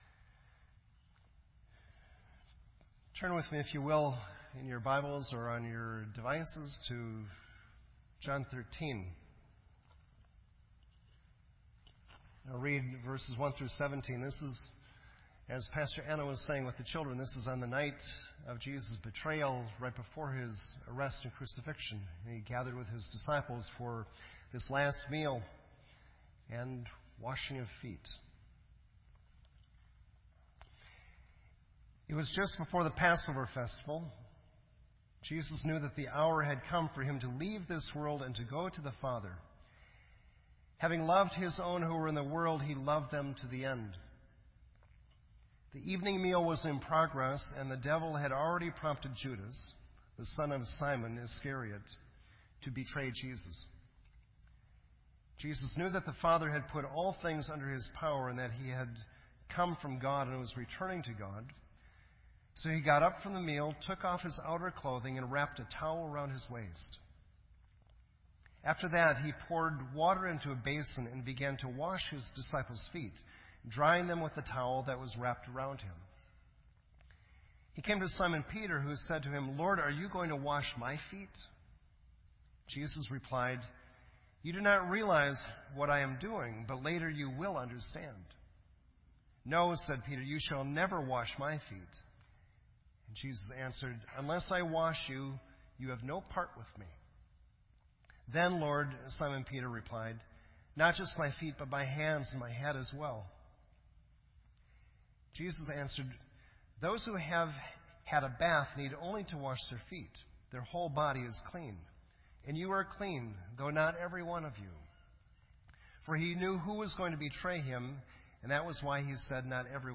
This entry was posted in Sermon Audio on March 19